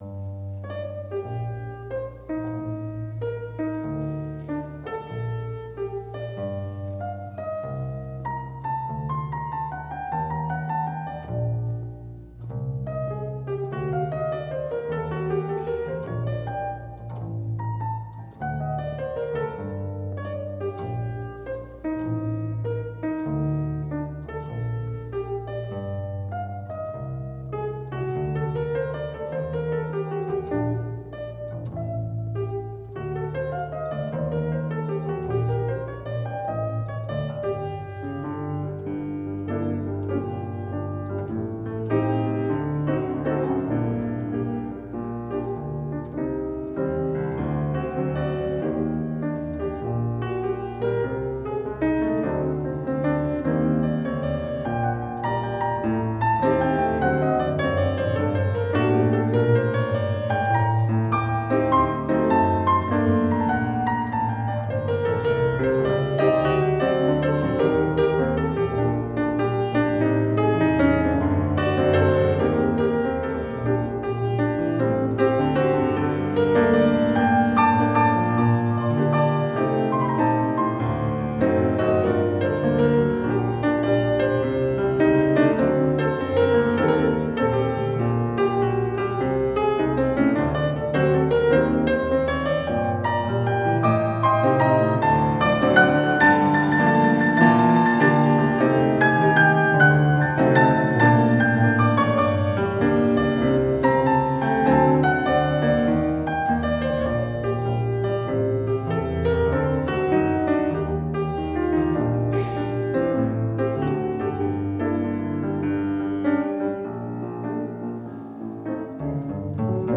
Piano
Gitarre
live in der Frauenkirche